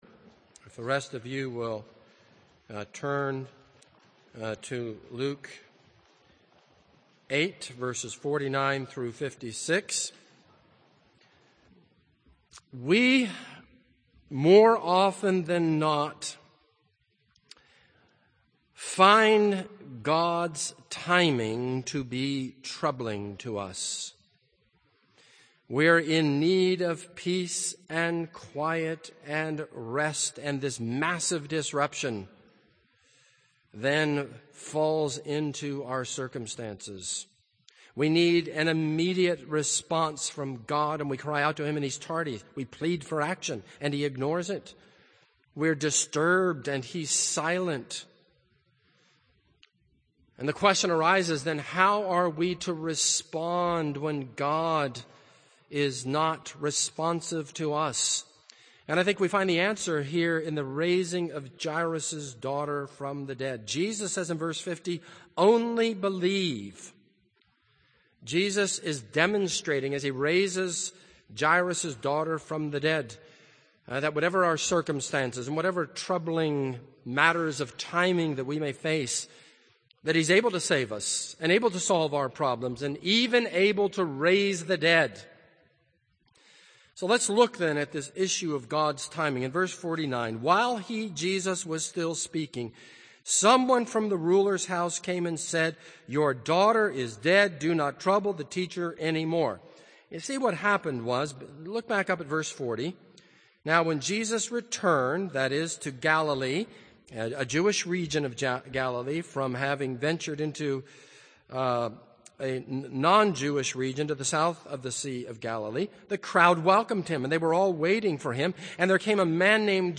This is a sermon on Luke 8:49-56.